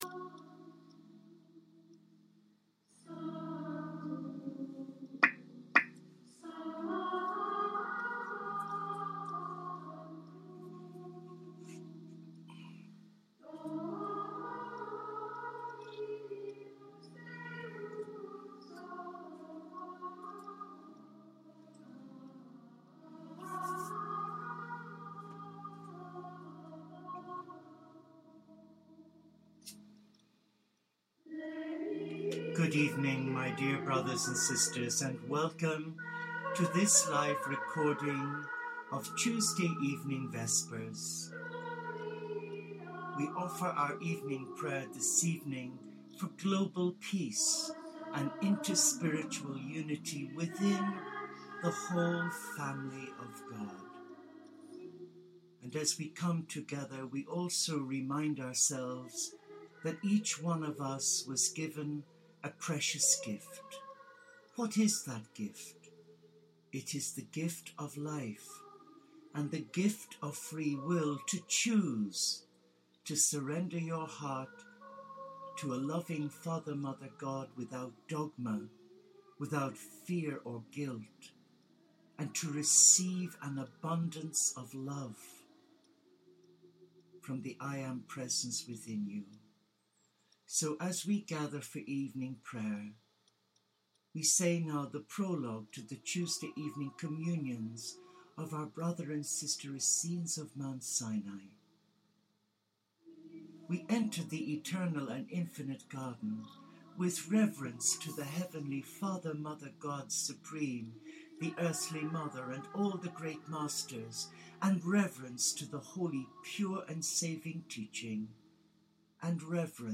Our vigil focused more on Finding who we are and embracing the Heart by Trusting The Divine Masculine (Jesus) and the Divine Feminine (Mary Magdalene) to self heal. Our vigil concluded with a short guided meditation under the Lilac tree in our Monastery Garden.